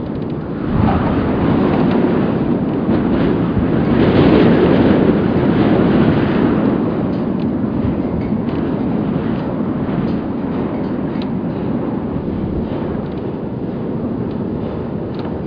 warehousewind.wav